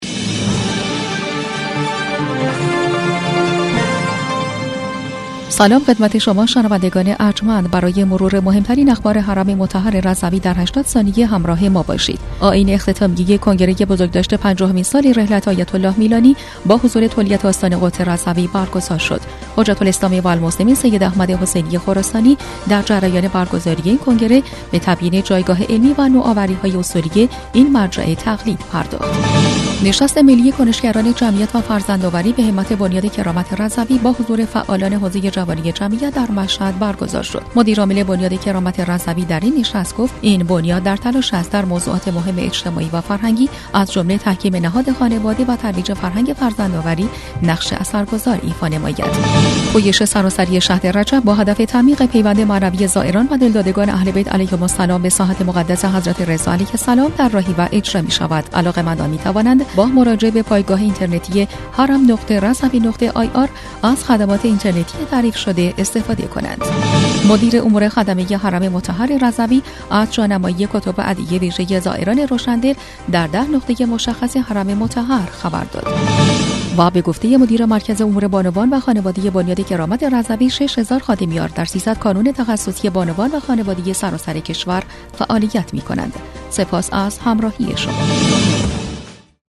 برچسب ها: صوت بسته خبری رادیو رضوی رادیو رضوی